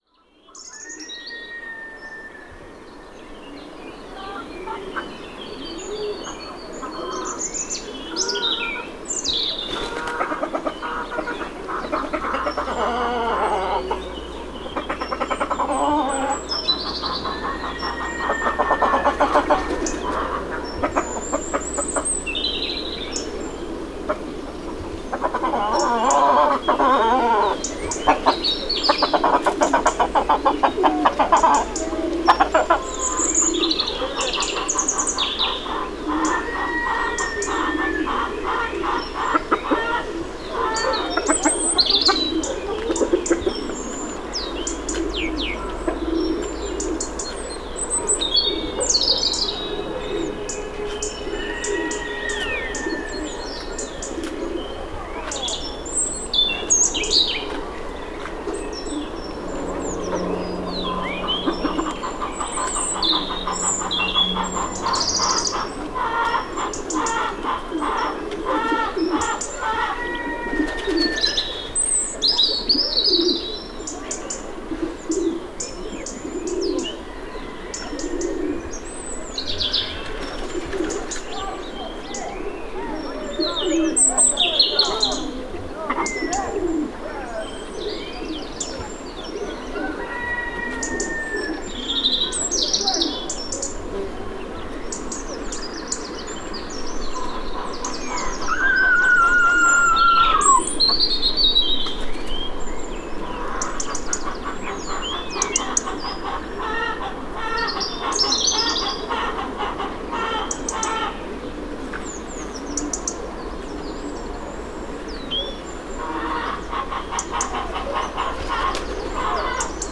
NODAR.00563 – Campo: Galinheiro junto a pocilga em Areeiro (de Campo)
Paisagem sonora de galinheiro junto a pocilga em Areeiro (de Campo), Campo a 8 Março 2016.
Num dia quente de Março no topo de um galinheiro encontramos um galo a repetir um som agudo monossilábico.